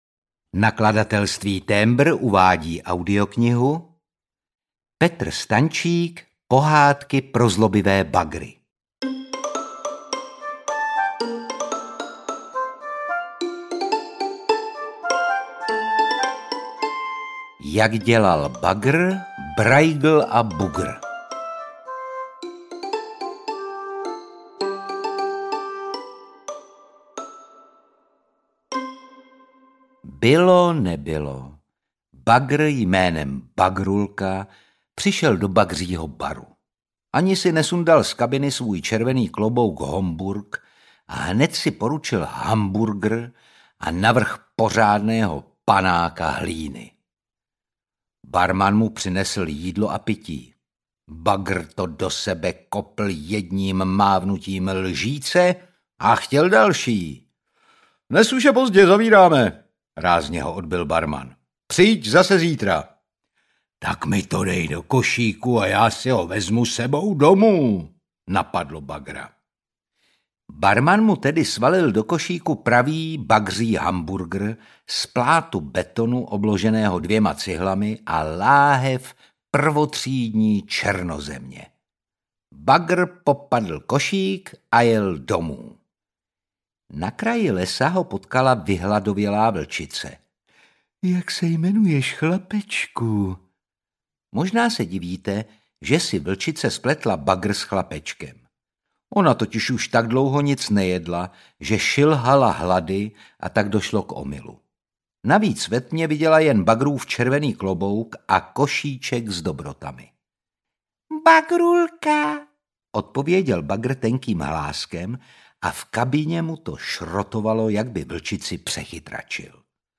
Pohádky pro zlobivé bagry audiokniha
Ukázka z knihy
• InterpretOtakar Brousek ml.